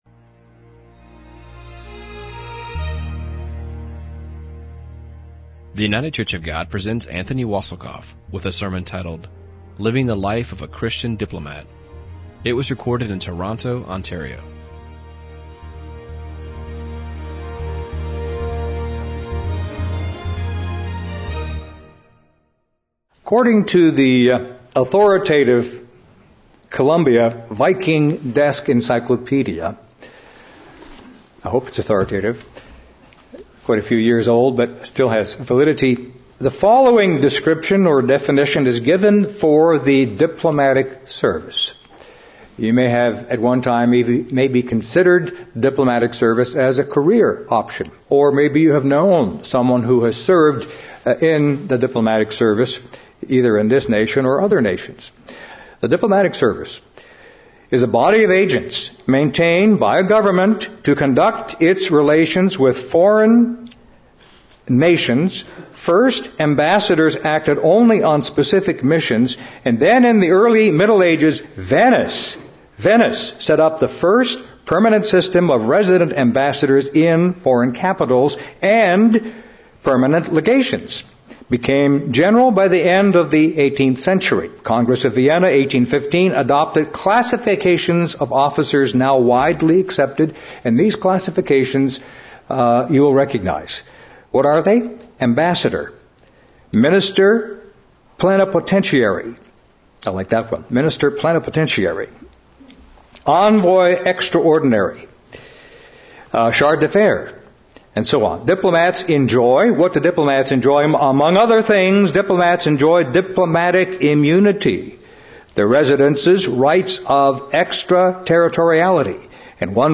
Sermon
2013 in the Toronto, Ontario, Canada congregation.